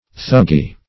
Search Result for " thuggee" : Wordnet 3.0 NOUN (1) 1. murder and robbery by thugs ; The Collaborative International Dictionary of English v.0.48: Thuggee \Thug*gee"\, n. [Hind.